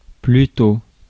Ääntäminen
France: IPA: [ply.tɔ]